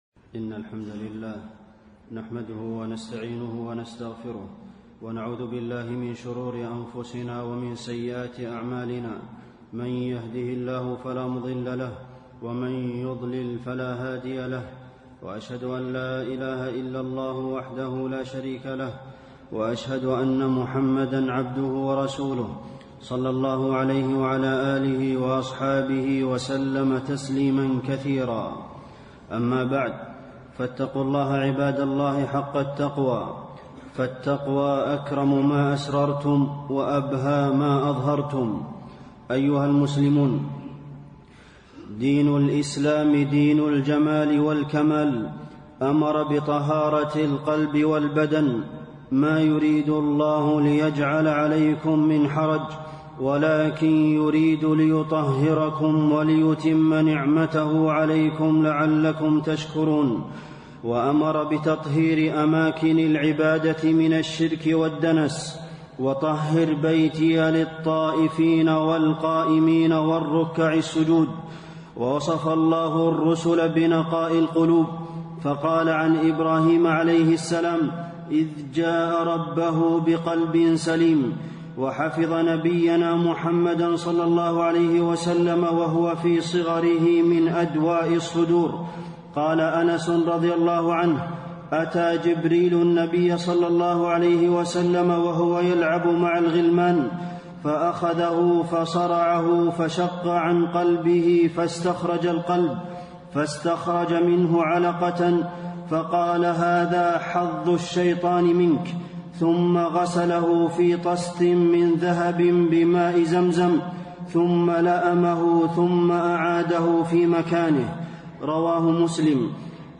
خطب الحرم المكي